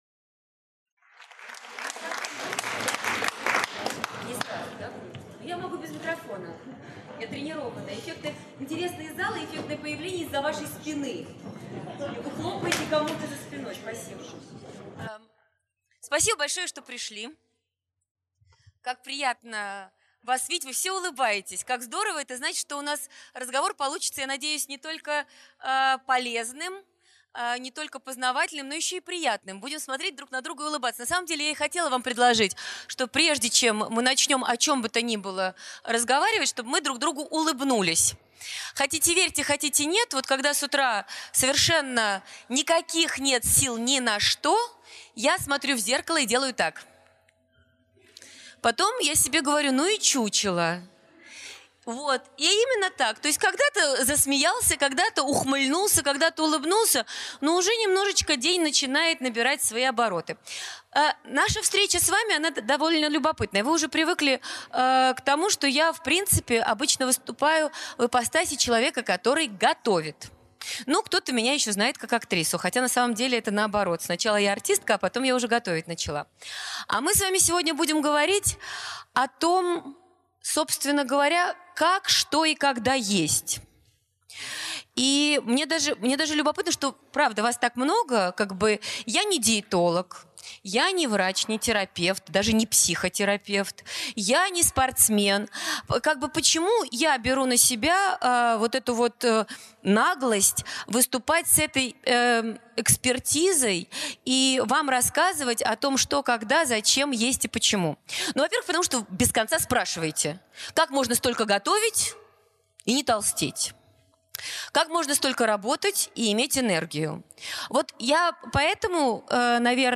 Аудиокнига Перезагрузка 1.0. с Юлией Высоцкой | Библиотека аудиокниг